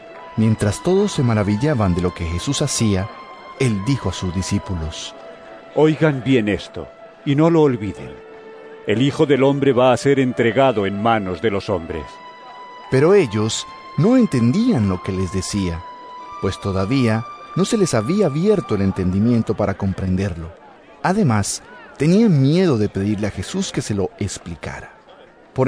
Lc 9 43b-45 EVANGELIO EN AUDIO